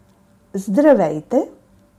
Just click on the languages below to hear how to pronounce “Hello”.